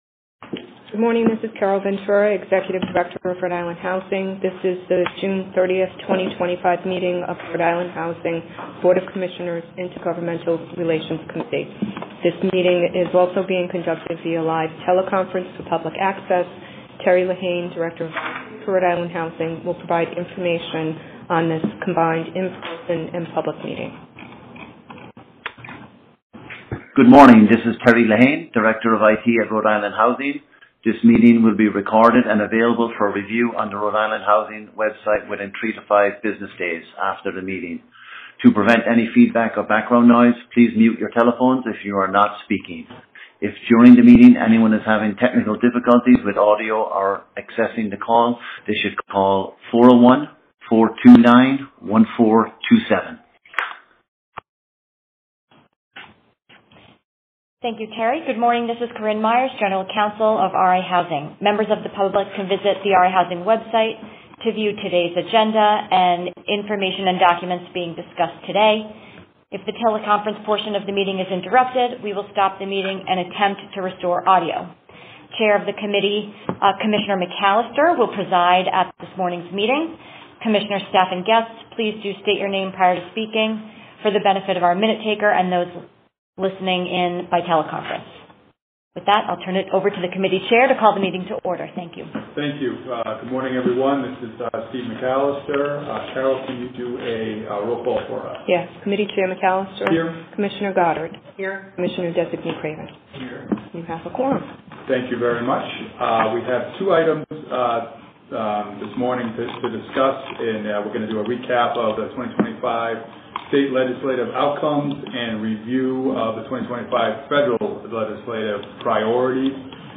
RIHousing Intergovernmental Relations Committee Meeting: 6.30.2025